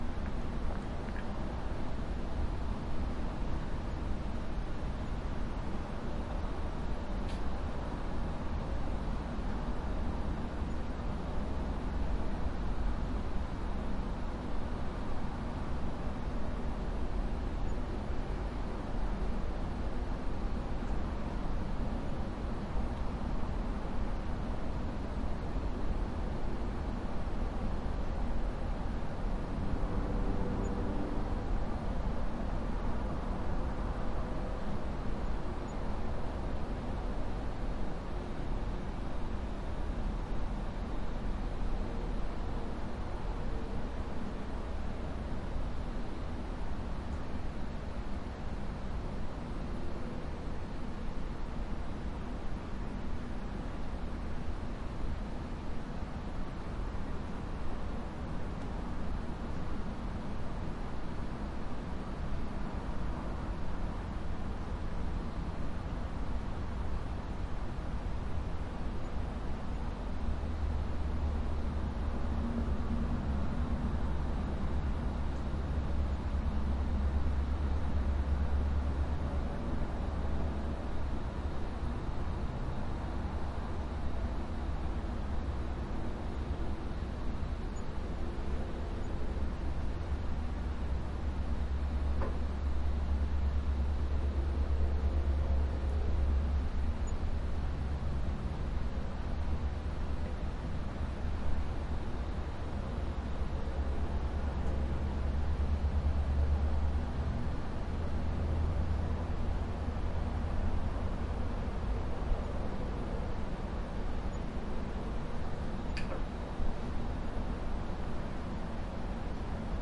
营地" 房间色调车库充满了垃圾，远处的高速公路交通繁忙，国家2
描述：房间音调车库充满垃圾与重型遥远的高速公路交通country2.flac
Tag: 公路 交通 远处 车库 国家 房间 色调